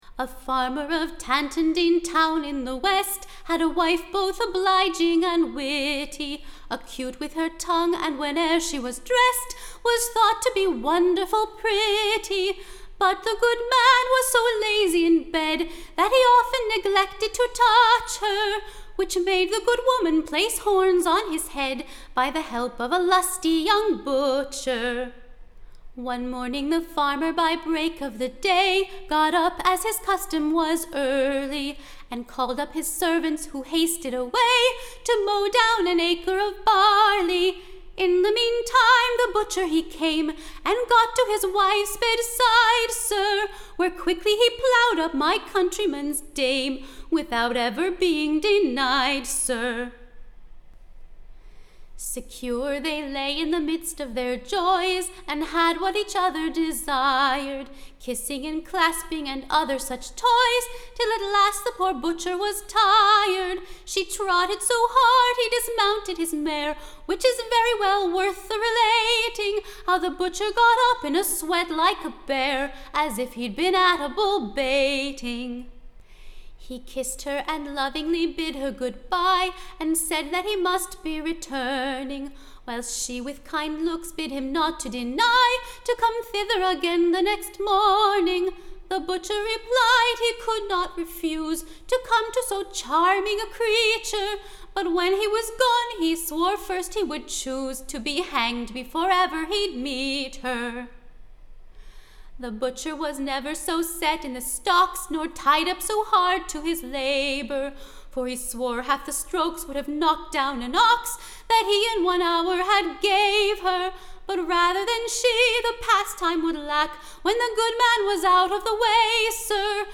Recording Information Ballad Title A New Western BALLAD, / Of a Butcher that Cuckolded the Farmer. / Good Husbands all be loving to your wives, / For that's the way to live contented lives; / But if you'r negligent, you may be sure / They'l ne'r want that they can elsewhere procure Tune Imprint Tune of, Ladies of London.